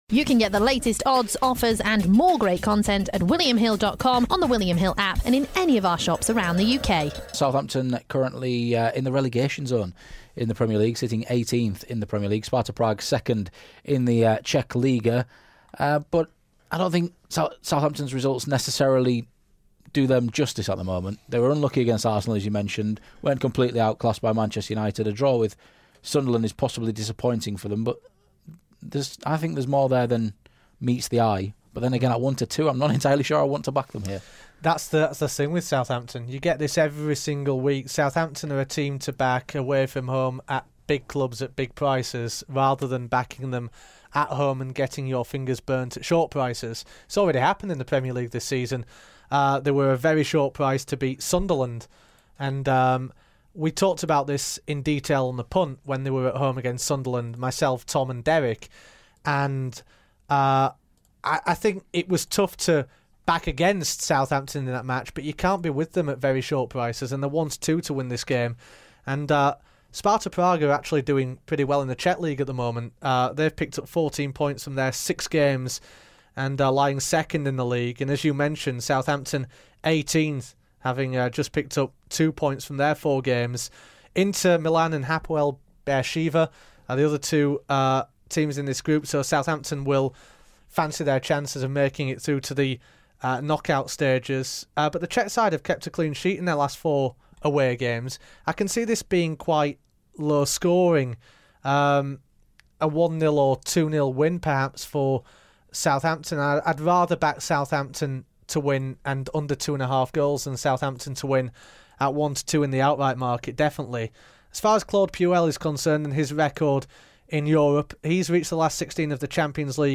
Southampton v Sparta Prague. This is an excerpt from The Punt podcast.